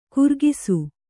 ♪ kurgisu